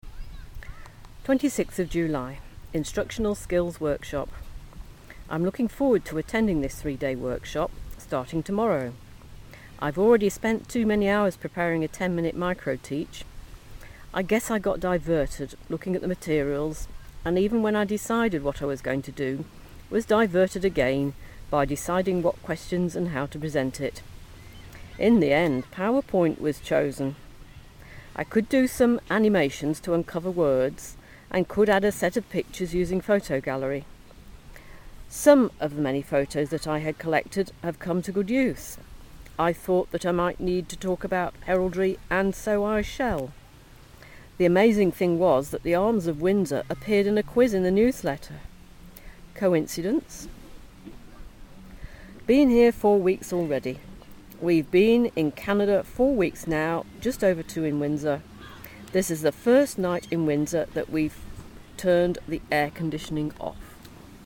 Instructional Skills Workshop (outside broadcast)